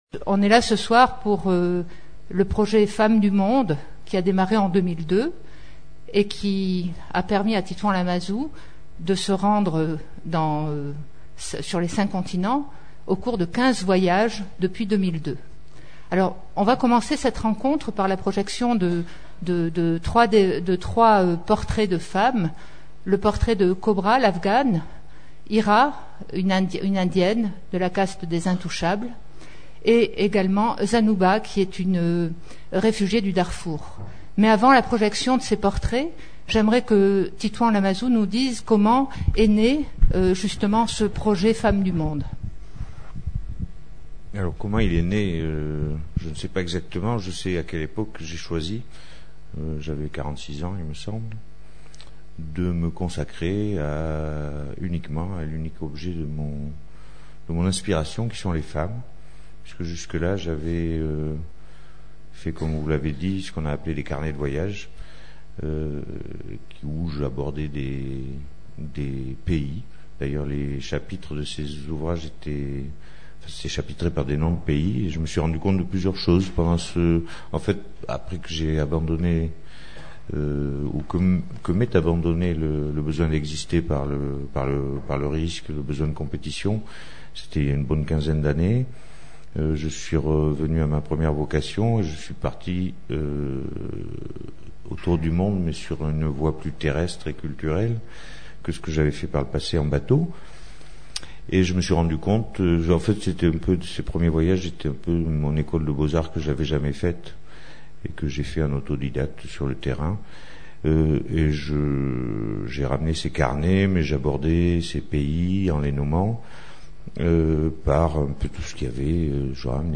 Lamazou, Titouan. Personne interviewée
Rencontre littéraire